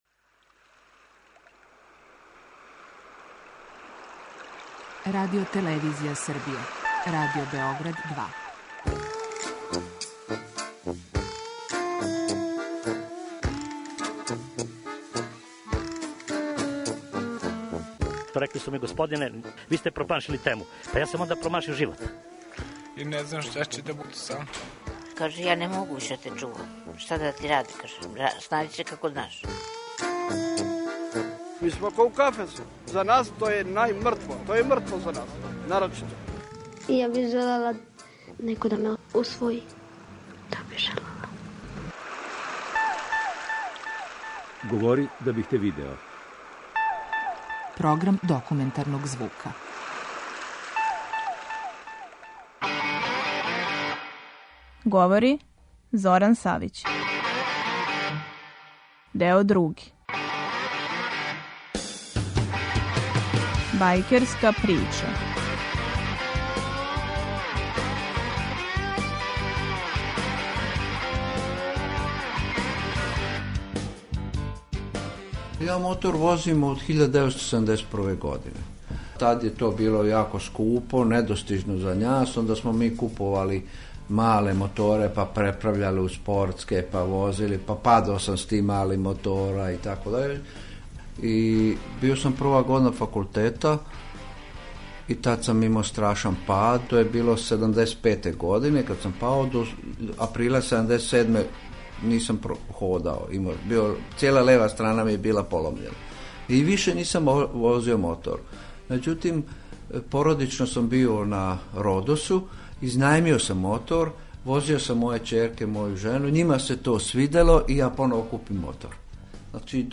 Други део документарне репортаже 'Бајкерска прича'